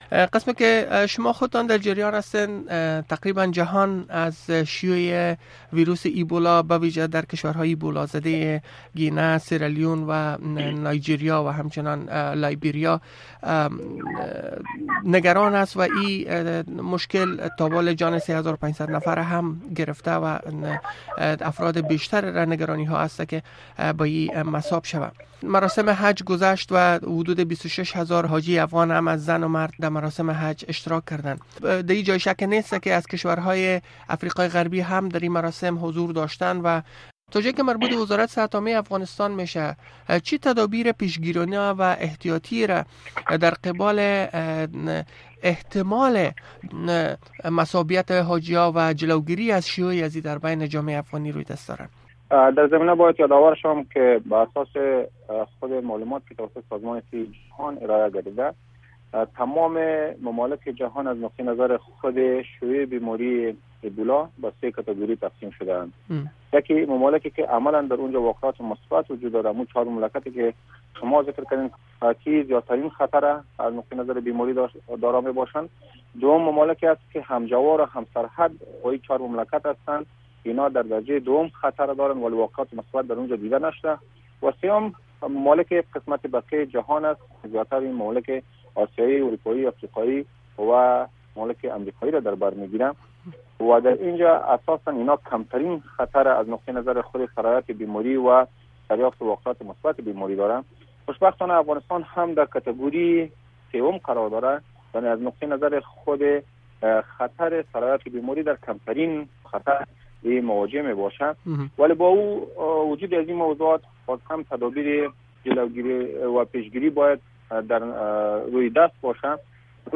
The URL has been copied to your clipboard No media source currently available 0:00 0:11:41 0:00 لینک دانلود | ام‌پی ۳ برای شنیدن مصاحبه در صفحۀ جداگانه اینجا کلیک کنید